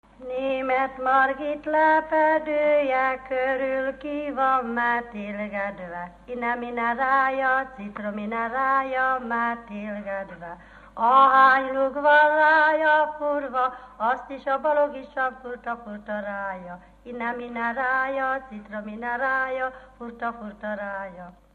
Dunántúl - Zala vm. - Ságod
ének
Műfaj: Párosító
Stílus: 6. Duda-kanász mulattató stílus